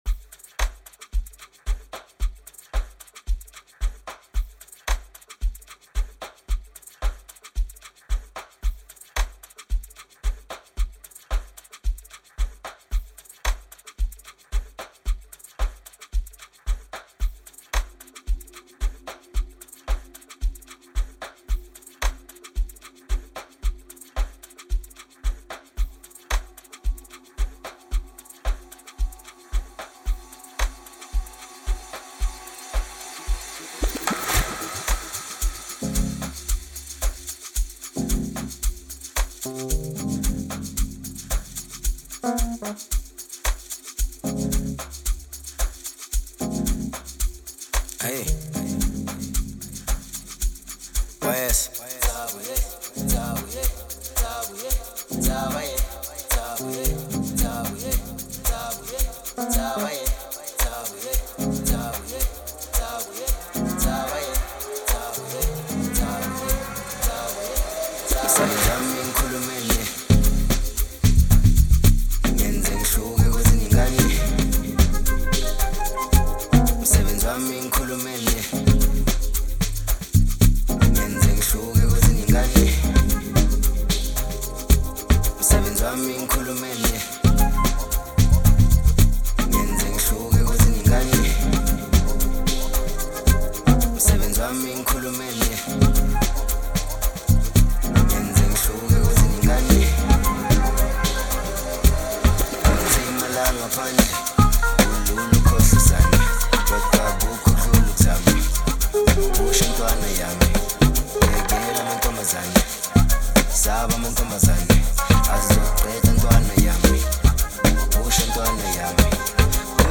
With its spreading melody and irresistible groove